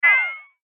pew.mp3